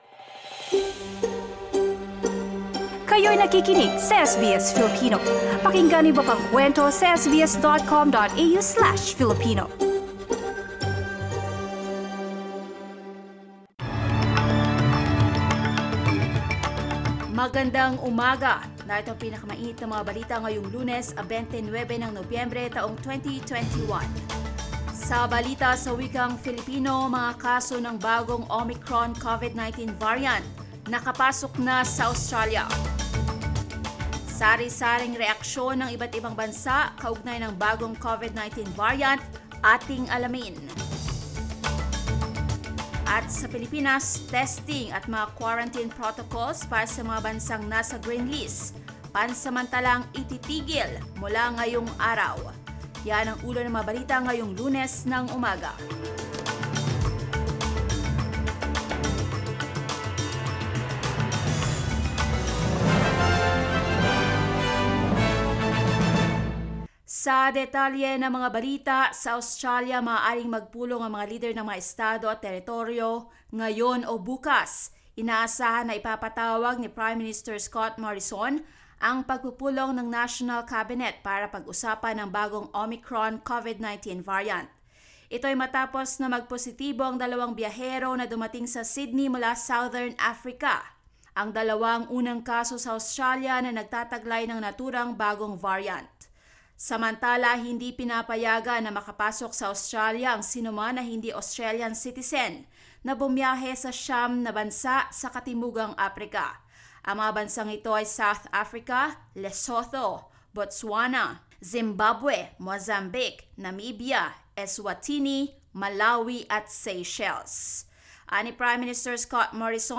Mga balita ngayong ika-29 ng Nobyembre